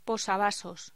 Locución: Posavasos
voz